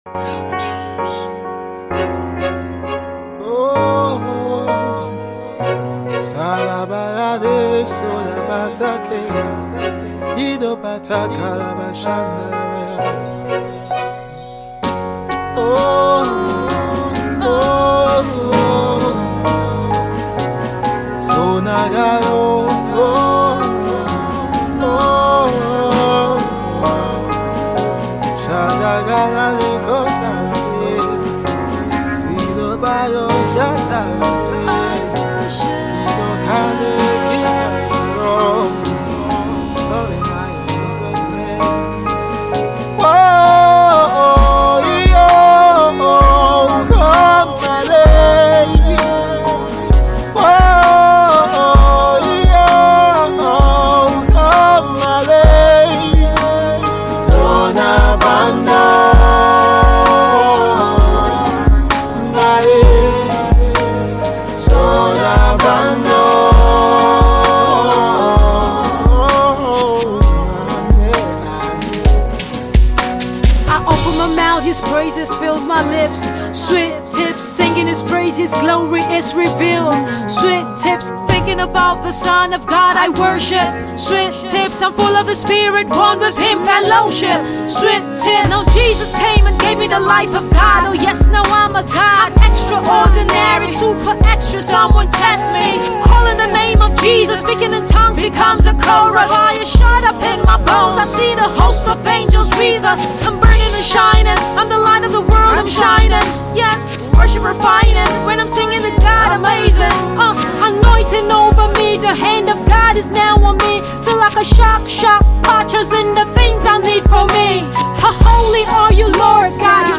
rap song
You can sing along in tongues too.